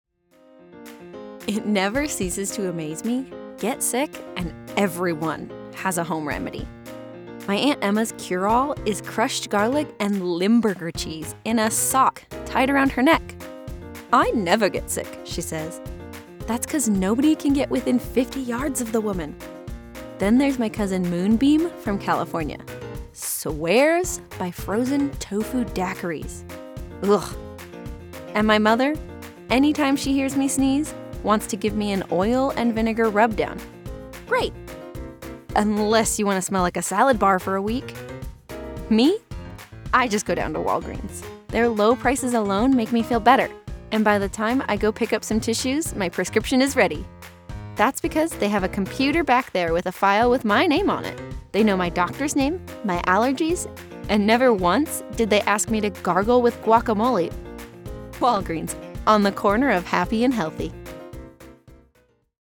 Walgreens Home Remedies - Online Ad Demo
I do voiceover full time from a professionally treated in-home studio using a Sennheiser MKH 416 microphone and Apollo Twin X Duo interface.
I was born just outside Seattle Washington, which means I offer that neutral accent that can be used nation wide.
Friendly, conversational, girl next door